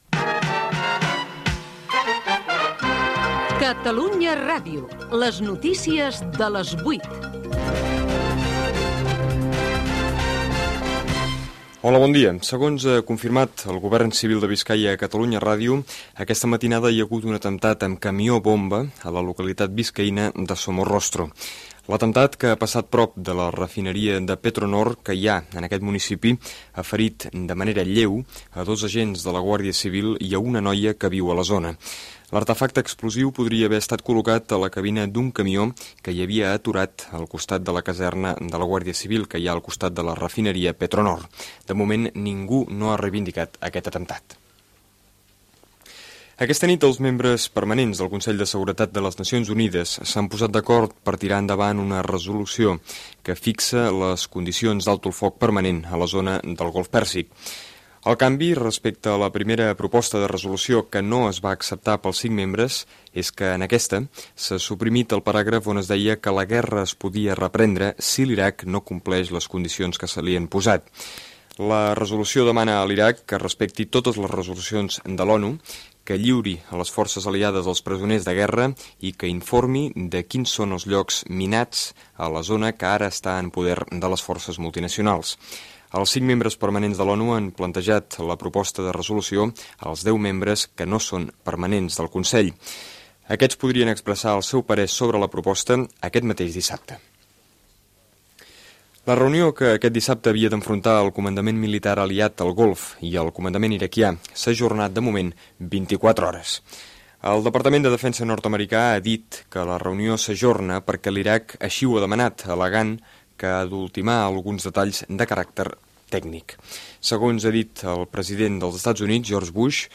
Careta d'entrada.
Careta de sortida.
Informatiu
FM